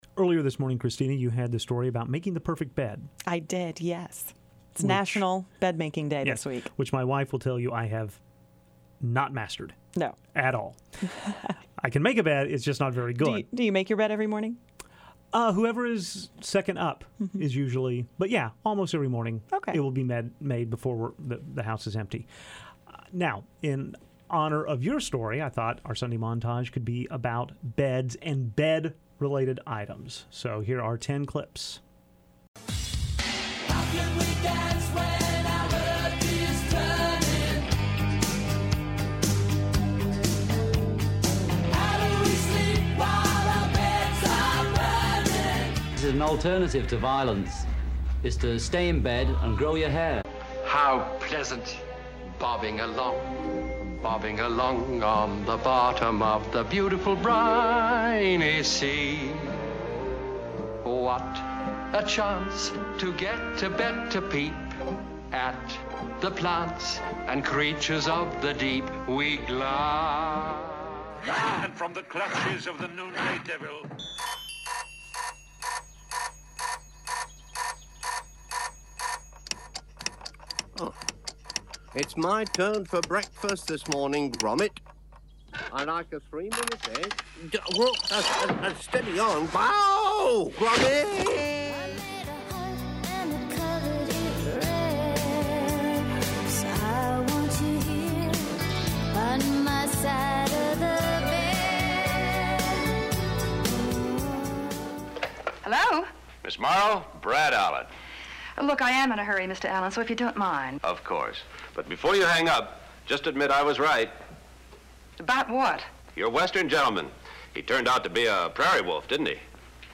John Lennon speaks from the John and Yoko "bed in" in Montreal. 3.
The Bangles sing My Side of the Bed. 7.